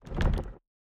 sfx_chest_jiggle_2.ogg